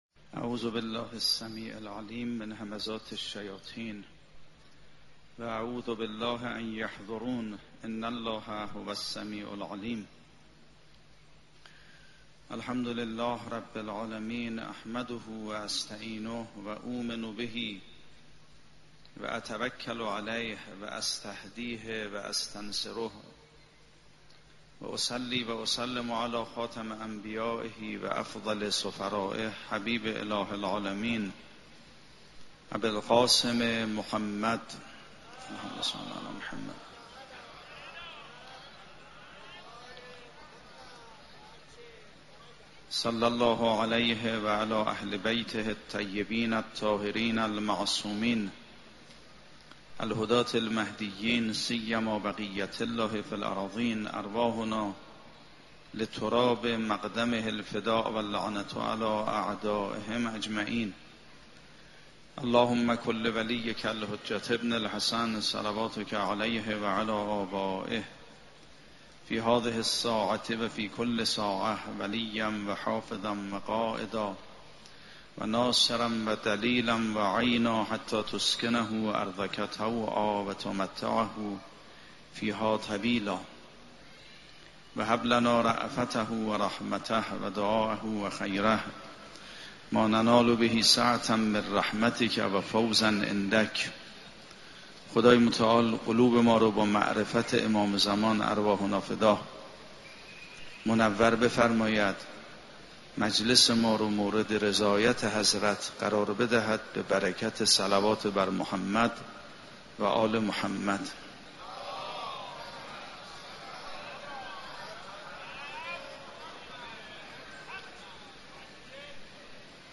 سخنرانی حجت الاسلام والمسلمین سیدمحمدمهدی میرباقری با موضوع اهمیت ماه محرم و عزاداری برای سیدالشهداء (ع)